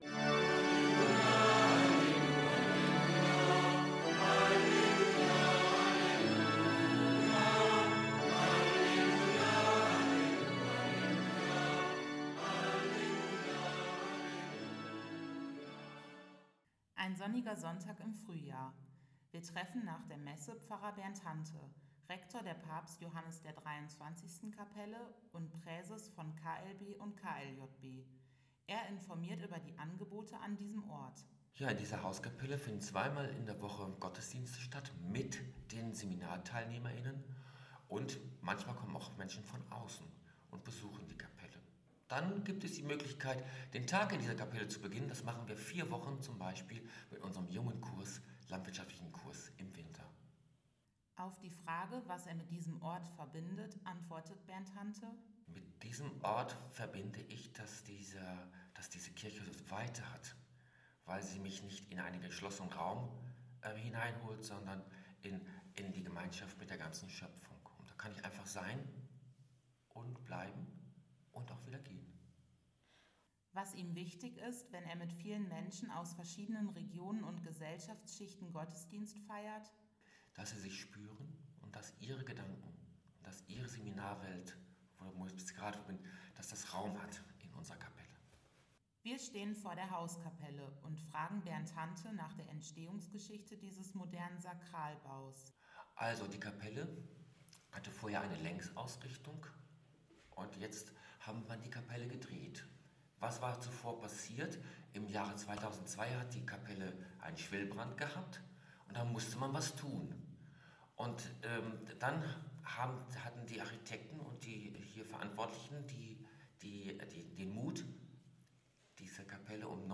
Sprecher